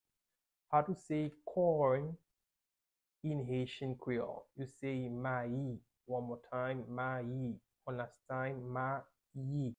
How to say "Corn" in Haitian Creole - "Mayi" pronunciation by a native Haitian Teacher
“Mayi” Pronunciation in Haitian Creole by a native Haitian can be heard in the audio here or in the video below:
How-to-say-Corn-in-Haitian-Creole-Mayi-pronunciation-by-a-native-Haitian-Teacher.mp3